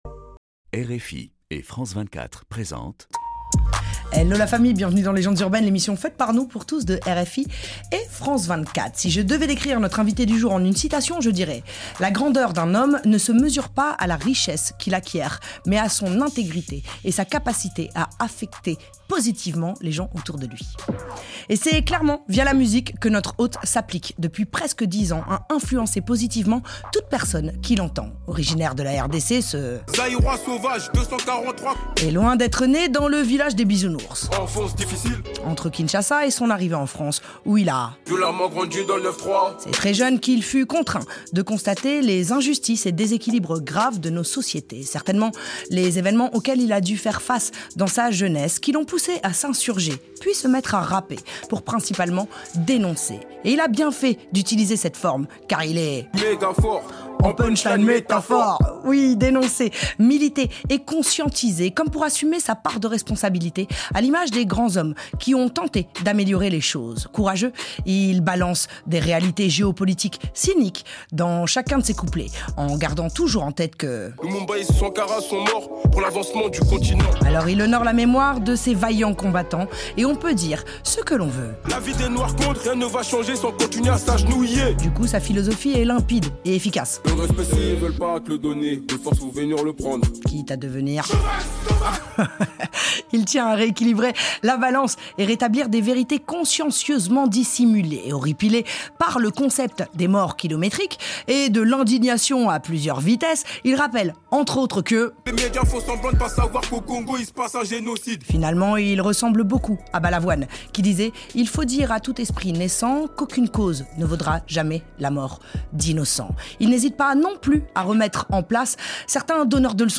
Au long de ces grands entretiens, artistes et professionnels (réalisateurs de clip, managers, tourneurs…), hommes et femmes